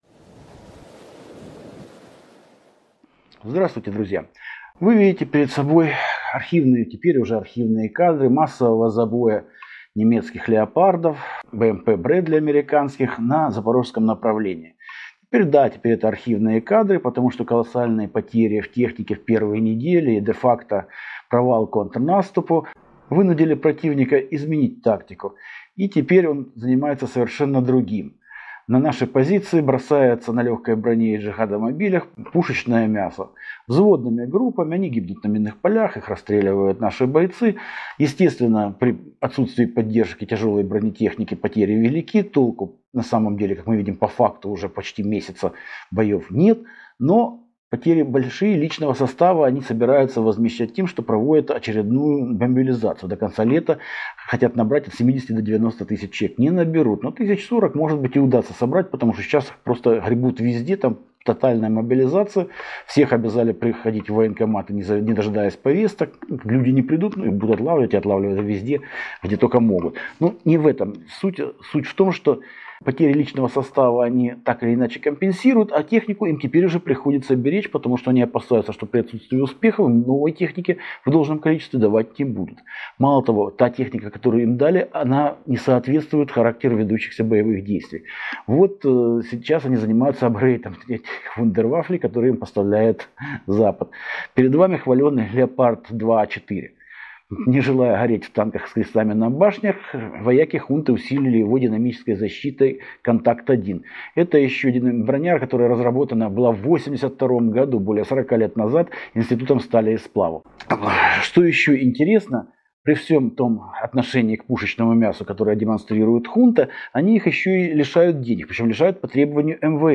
Утренний обзор в аудио формате: